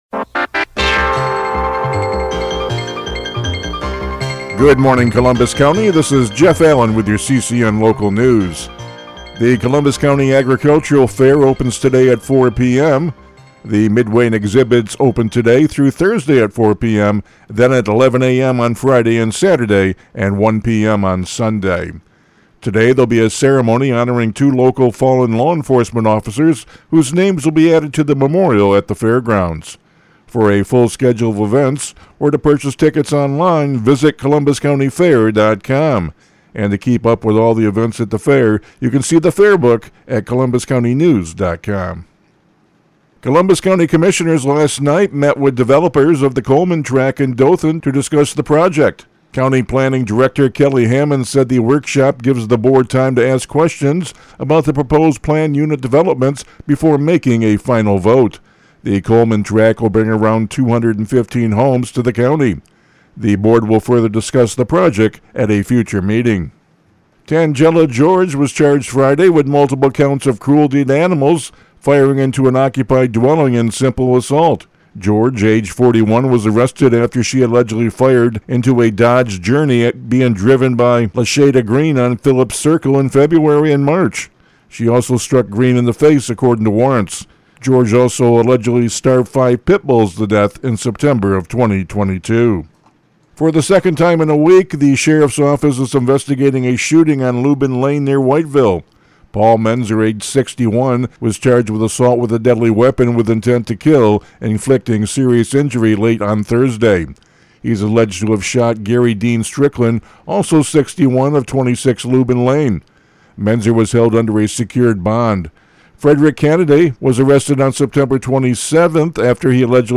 CCN Radio News — Morning Report for October 7, 2025